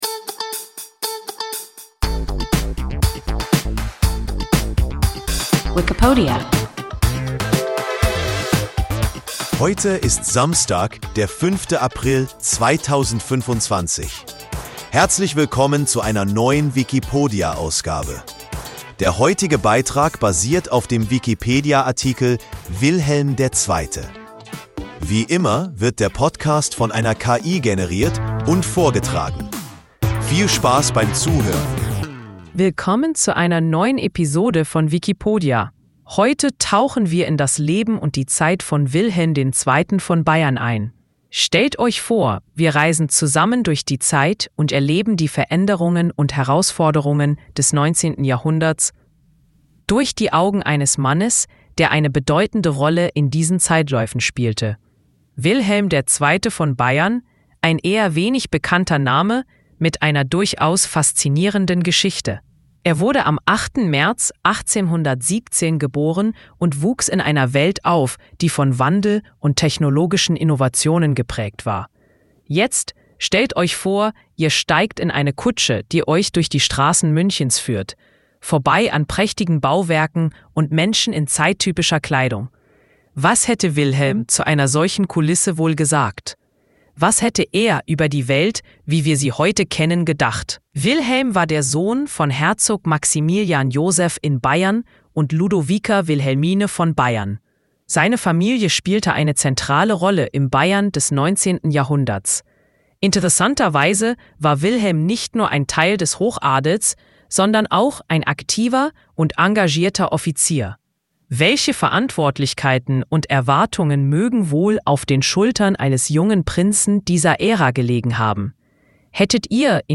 Wilhelm II. (Bayern) – WIKIPODIA – ein KI Podcast